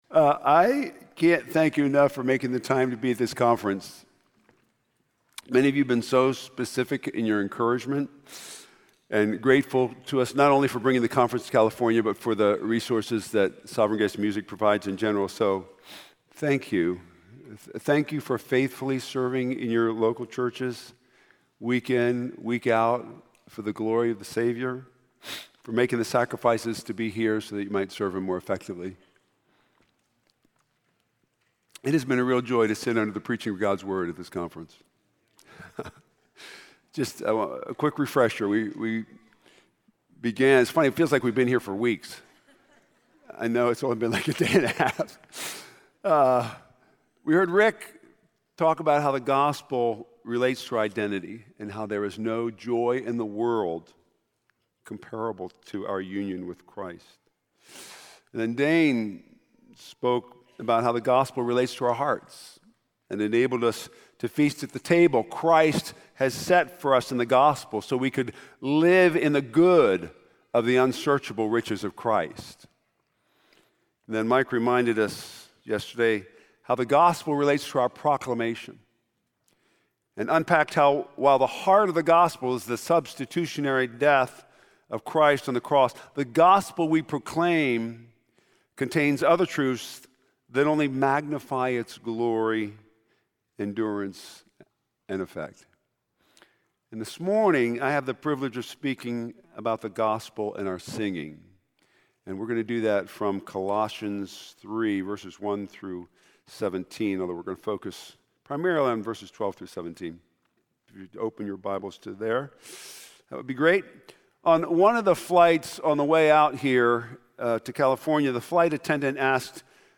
Conference Messages